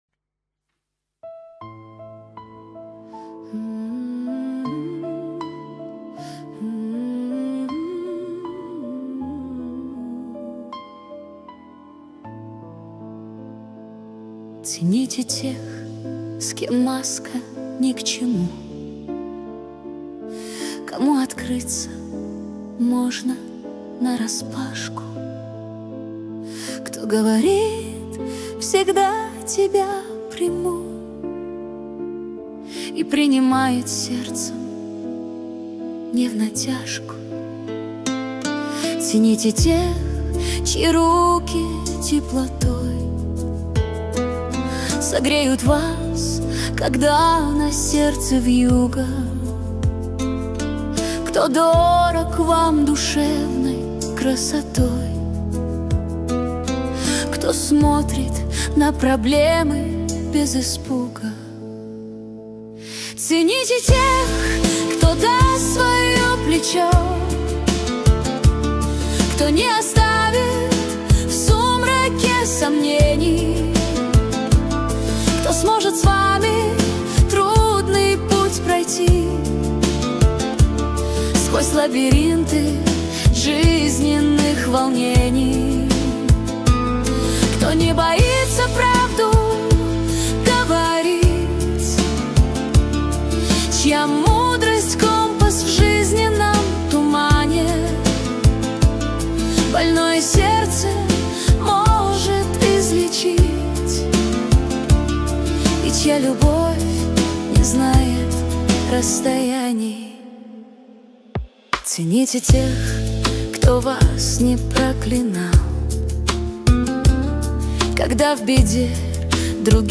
13 декабрь 2025 Русская AI музыка 75 прослушиваний